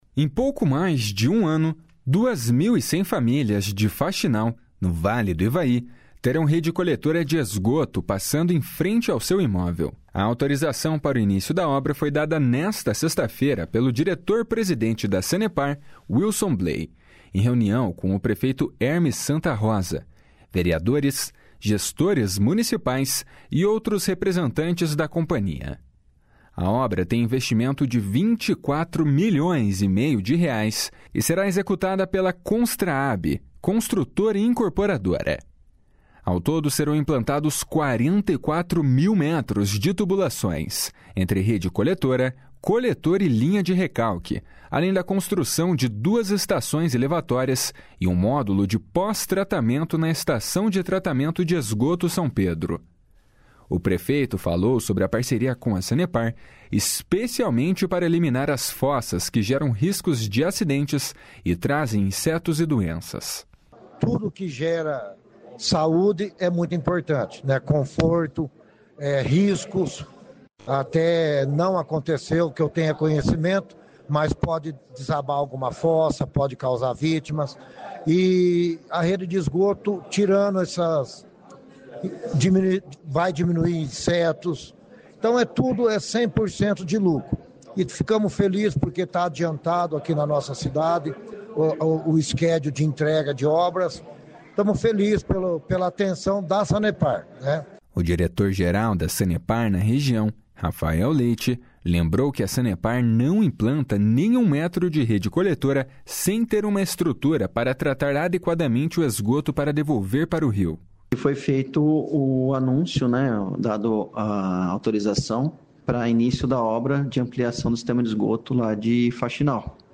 O prefeito falou sobre a parceria com a Sanepar, especialmente para eliminar as fossas que geram riscos de acidentes e trazem insetos e doenças. // SONORA HERMES SANTA //